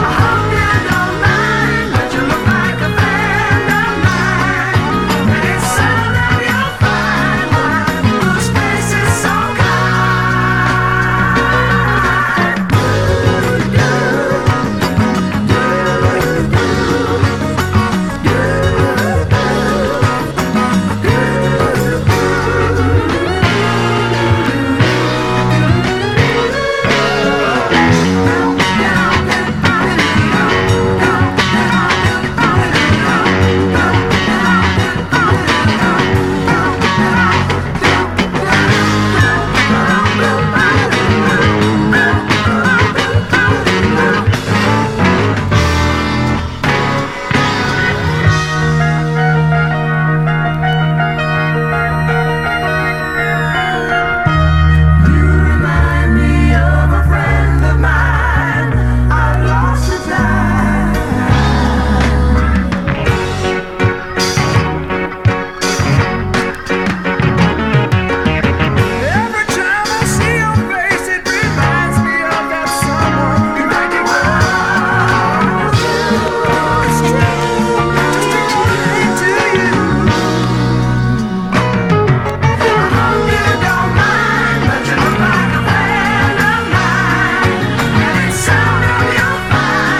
ROCK / SOFTROCK. / PSYCHEDELIC (US)
清らかなフォーキィ・ソフトロック/サイケデリック・ハーモニー・ポップの名曲ズラリ！
たおやかなメロが爽快な男女混声ハーモニーで紡がれる